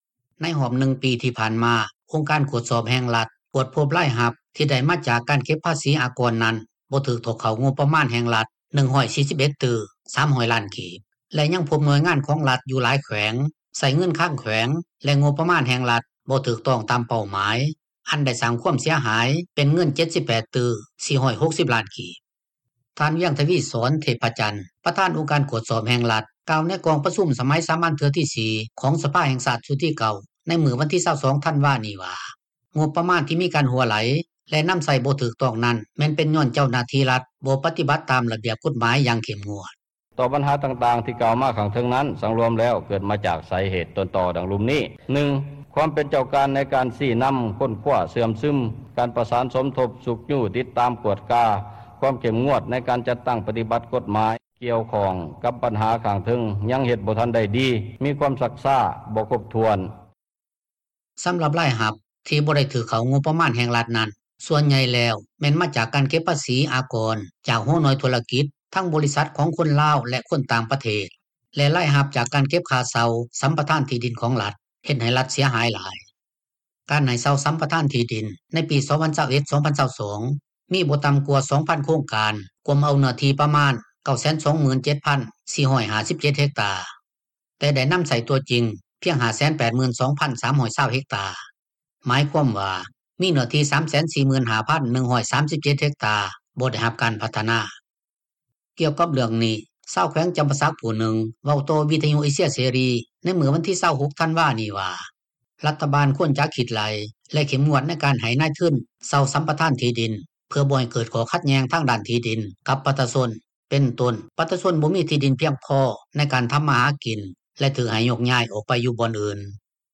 ກ່ຽວກັບເຣື່ອງນີ້ ຊາວແຂວງຈໍາປາສັກຜູ້ນຶ່ງ ເວົ້າຕໍ່ວິທຍຸ ເອເຊັຽ ເສຣີ ໃນມື້ວັນທີ 26 ທັນວານີ້ວ່າ ຣັຖບານຄວນຈະຄິດໄລ່ ແລະເຂັ້ມງວດ ໃນການໃຫ້ນາຍທຶນເຊົ່າ-ສັມປະທານທີ່ດິນ ເພື່ອບໍ່ເກີດຂໍ້ຂັດແຍ່ງ ທາງດ້ານທີ່ດິນ ກັບປະຊາຊົນ ເປັນຕົ້ນ ປະຊາຊົນບໍ່ມີທີ່ດິນ ບໍ່ພຽງພໍໃນການທໍາມາຫາກິນ ແລະຖືກໃຫ້ໂຍກຍ້າຍ ອອກໄປຢູ່ບ່ອນອື່ນ. ຖ້າຫາກມີເນື້ອທີ່ດິນຈໍາກັດ ກໍບໍ່ຄວນຈະປ່ອຍໃຫ້ເຊົ່າ ຫລືສັມປະທານ, ຄວນຮັກສາໄວ້ ໃຫ້ຊາວບ້ານ ທໍາມາຫາກິນລ້ຽງຊີພ.
ດັ່ງທີ່ທ່ານ ຄໍາພັນ ພົມມະທັດ ກ່າວວ່າ: